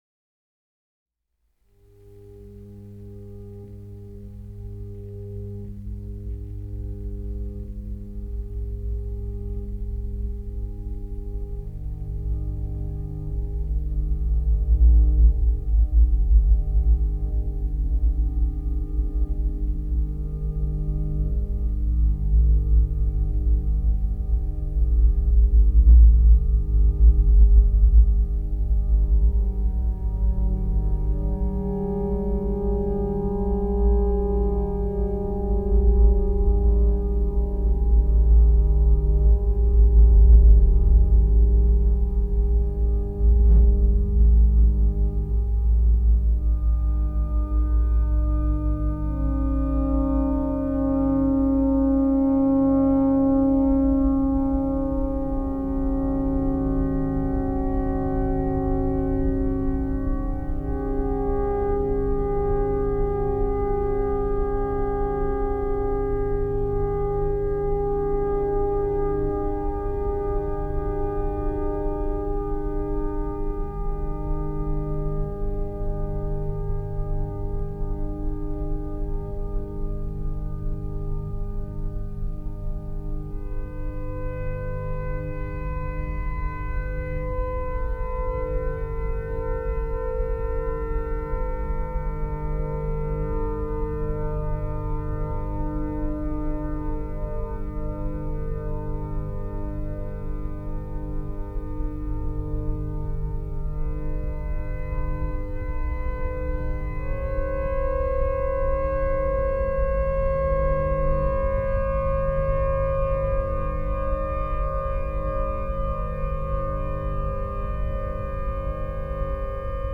saxophone
guitar
spoken word